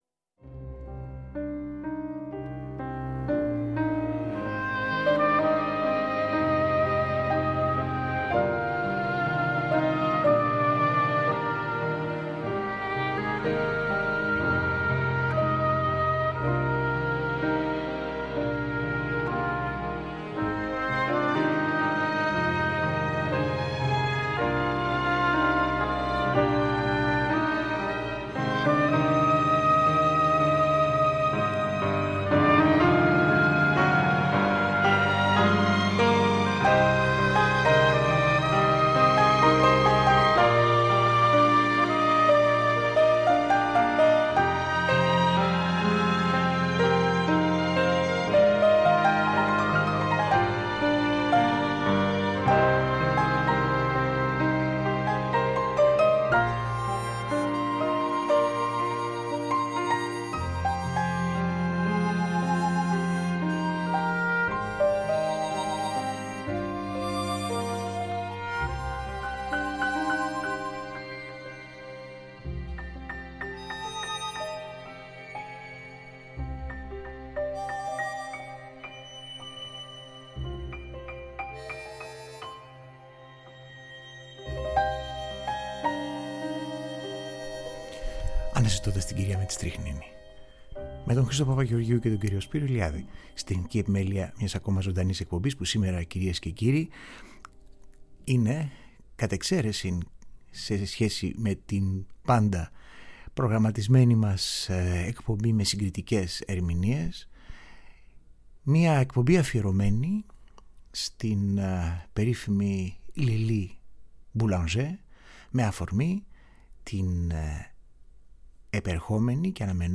ζωντανά στο Τρίτο Πρόγραμμα μιλούν και παρουσιάζουν τα έργα της σημαντικής όσο και σπάνιας αυτής συναυλίας.